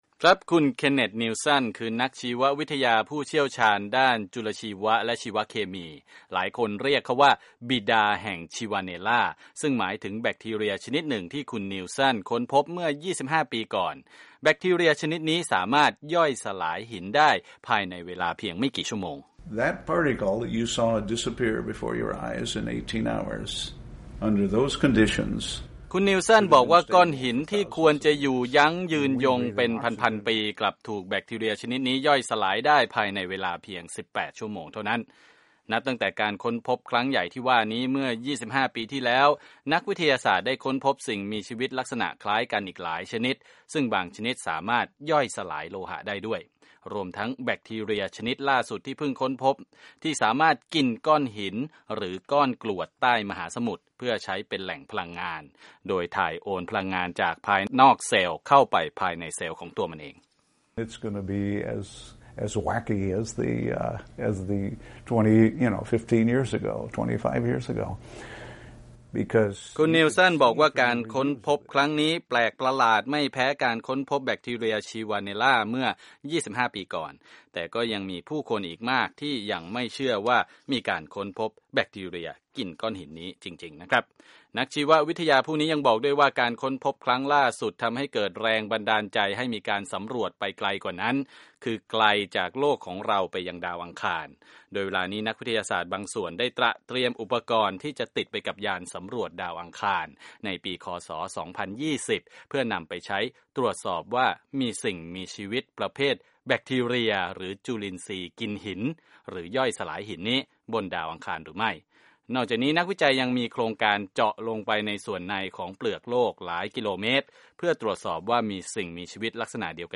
รายงานจากผู้สื่อข่าววีโอเอ